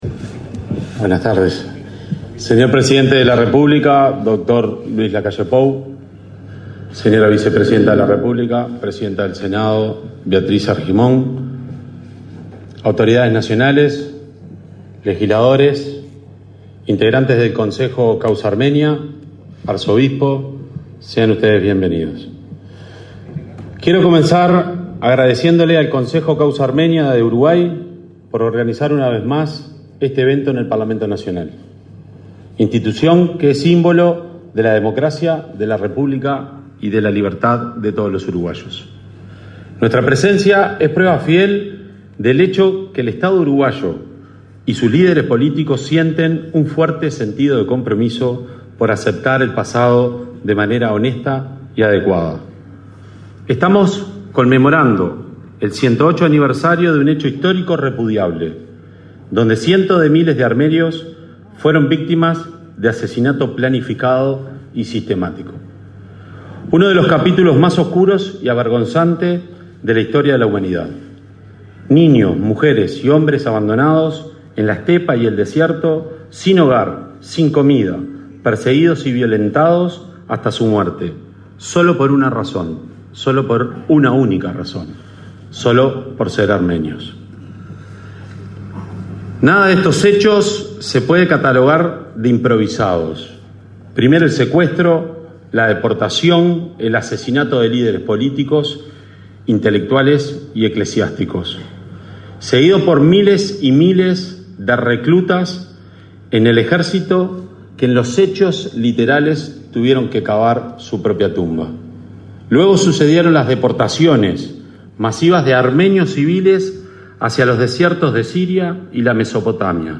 Conferencia de prensa por el aniversario del Genocidio Armenio
Con la presencia del presidente de la República, Luis Lacalle Pou, este 24 de abril se realizó el acto de conmemoración del 108.° aniversario del